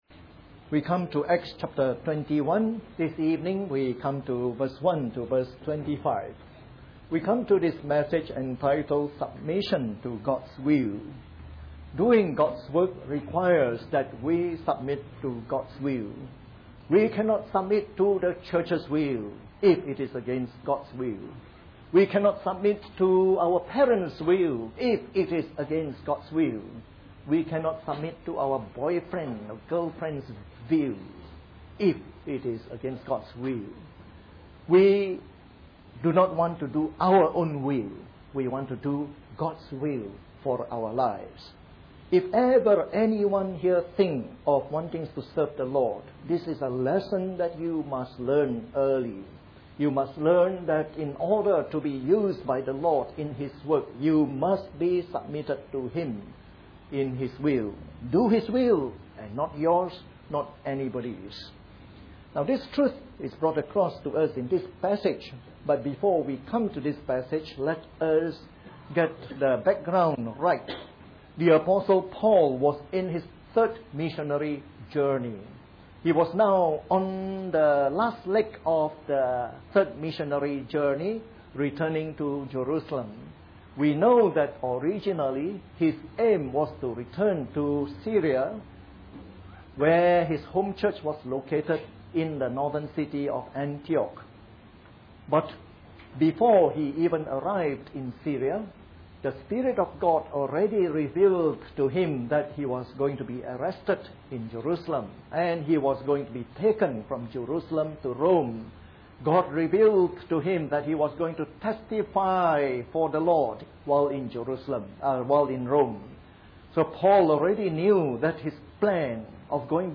Preached on the 29th of April 2012. Part of our series on “The Acts of the Apostles” delivered in the Evening Service.